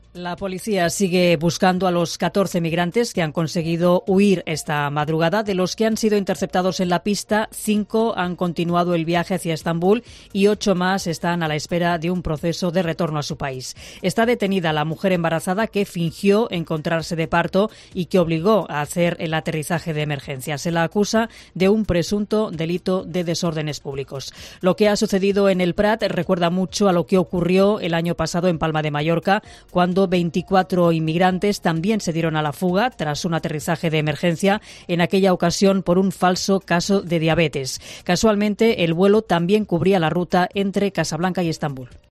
crónica
desde el Aeropuerto de El Prat